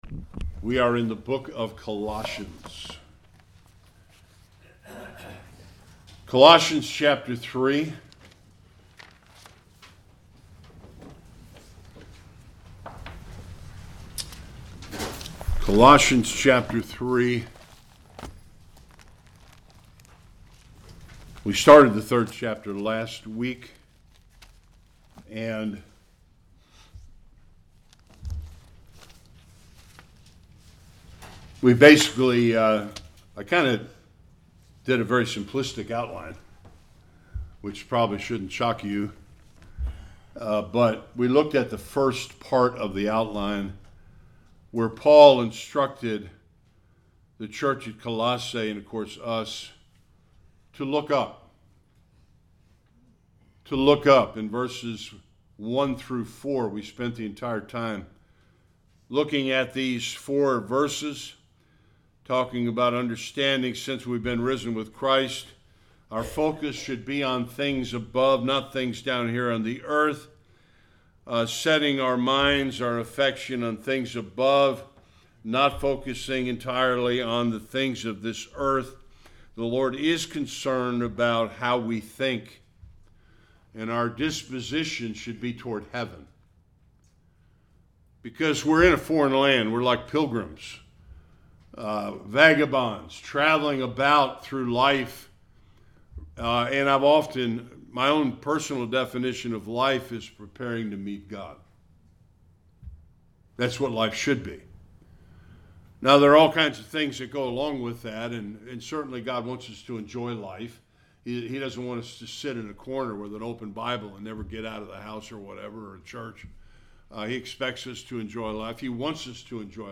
5-11 Service Type: Sunday Worship The last 2 of 3 life principles for Christians.